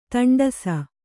♪ taṇḍasa